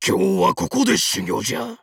Master Mantis's voice from the official Japanese site for WarioWare: Move It!
WWMI_JP_Site_Mantis_Voice.wav